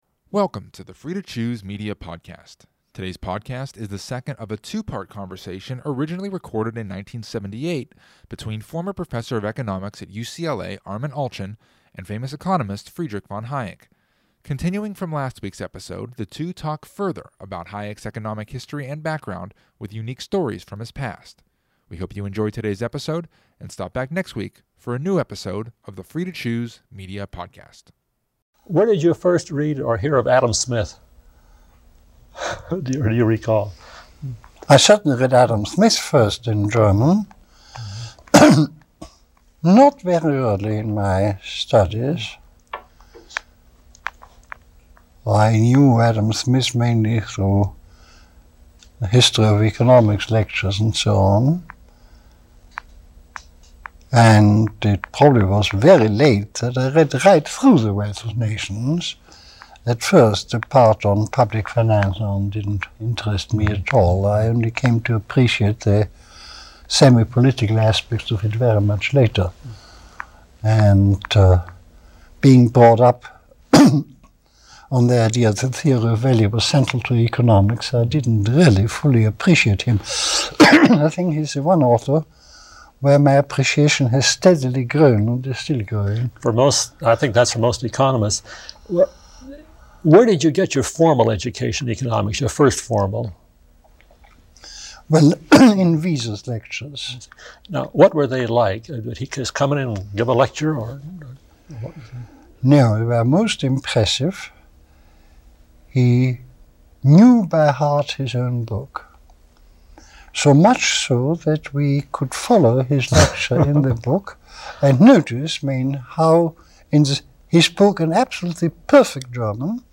Today's podcast is the second of a two-part conversation, originally recorded in 1978, between former Professor of Economics at UCLA, Armen Alchian, and famous economist Friedrich von Hayek.